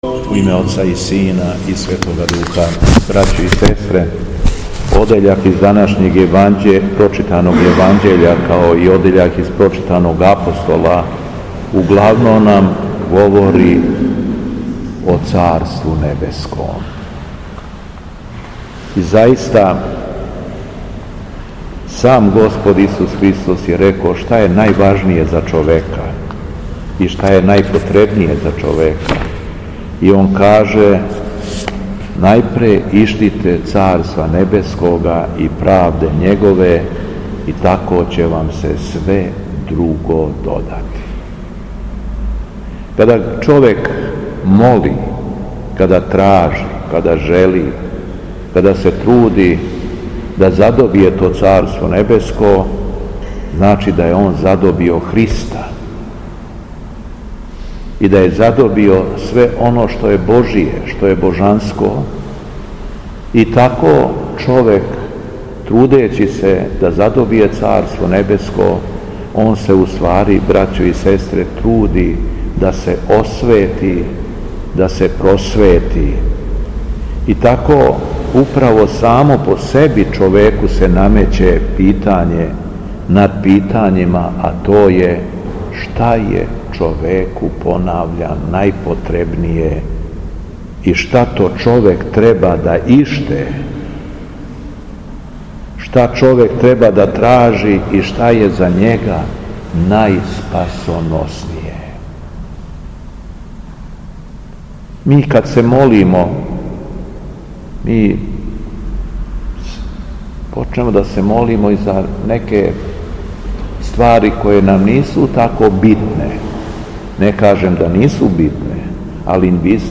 У понедељак двадесет и четврти по Духовима, када наша Света Црква прославља светог Варлама и Јоасафа, Његово Високопреосвештенство Митрополит шумадијски господин Јован, служио је свету архијерејску литургију у храму Светога Саве у крагујевачком насељу Аеродром.
Беседа Његовог Високопреосвештенства Митрополита шумадијског г. Јована
У наставку се Високопреосвећени обратио верном народу надахнутим речима: